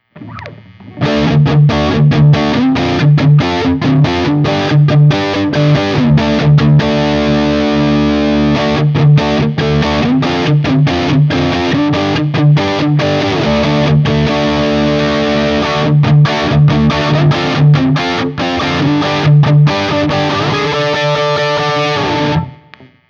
I recorded using the ODS100 Clean patch, as well as the JCM-800 and one through a setting called Citrus which is the “I wish it was still the ’80s” Orange amp simulation that I like so much. For each recording I cycle through the neck pickup, both pickups, and finally the bridge pickup.
This guitar has a very dark sound and the neck pickup especially turns to mud very easily if you just look at the tone knob.